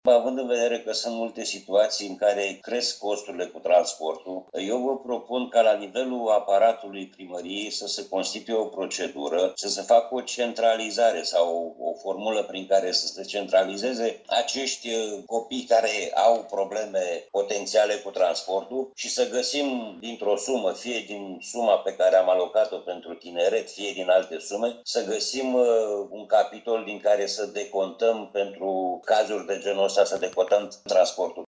Desfășurată în format online, ședința de azi a Consiliului Local Constanța a avut pe ordinea de zi 3 proiecte de hotărâre.